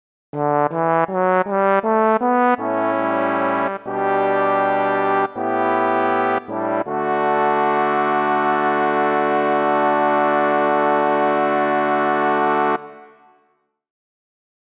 Key written in: D Major
How many parts: 4
Type: Barbershop
All Parts mix: